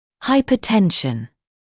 [ˌhaɪpə(u)’tenʃn][ˌхайпо(у)’тэншн]гипотензия, гипотония